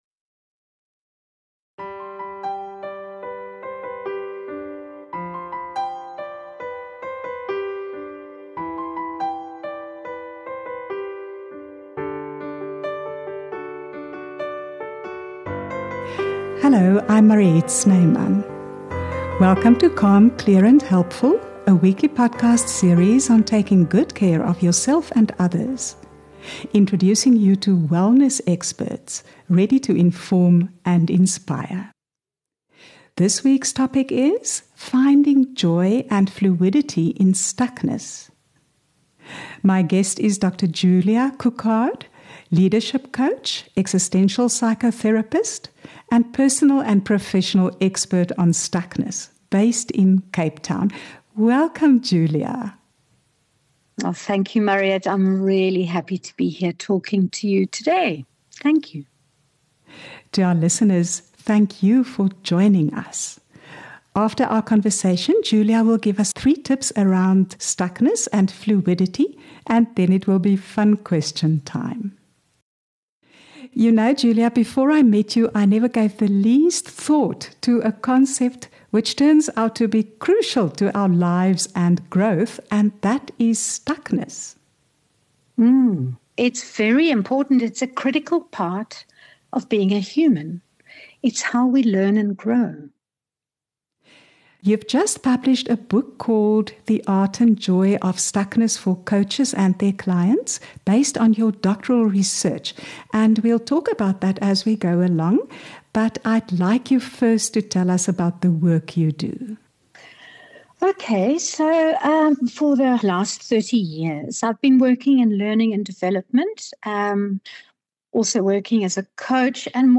Join us each Tuesday for fresh insights, practical know-how, and for conversations from the heart.